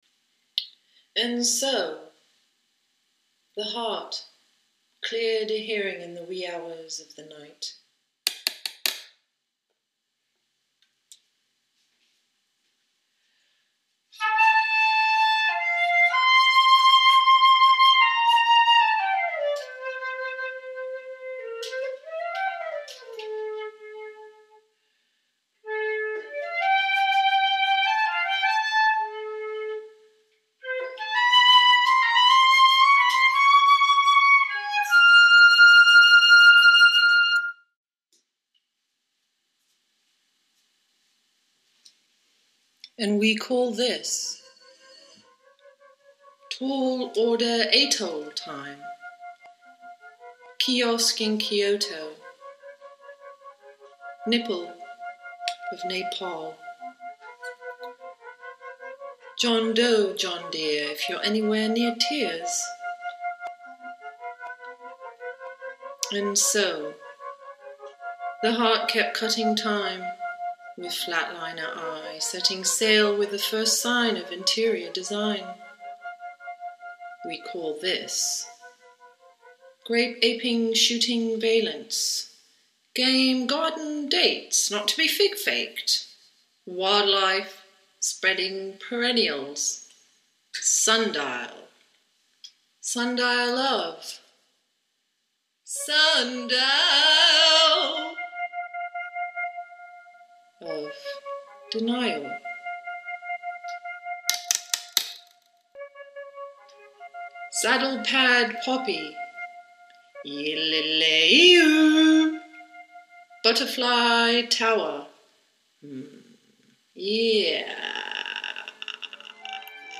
Tags: poetry soundscapes